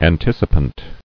[an·tic·i·pant]